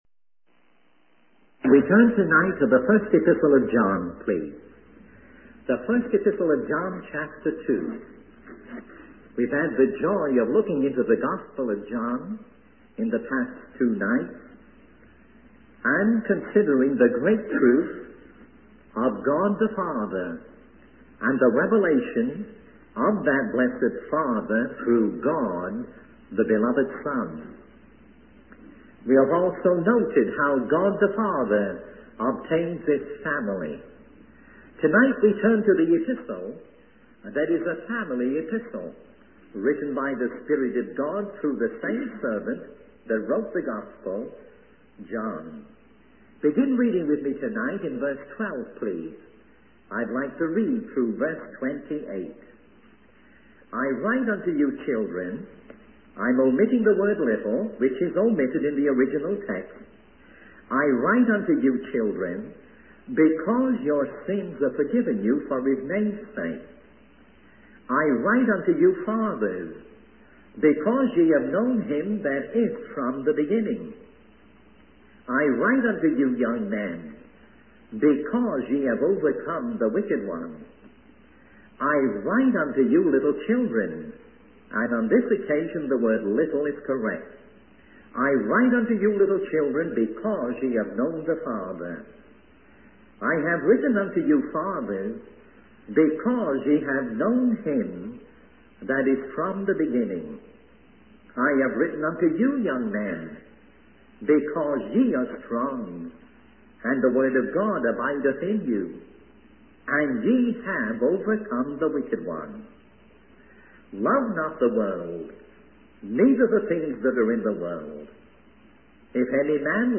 In this sermon, the speaker shares his personal experience of witnessing many souls being saved in a certain region of New York State.